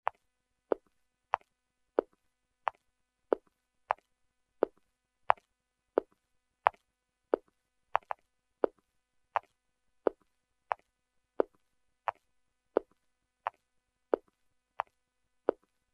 农场氛围 " 门开了又关，脚步穿过草地和沙砾
描述：户外马笔门打开，脚步声，门关闭。在草和石渣的脚步声
Tag: 脚步 硬件 砾石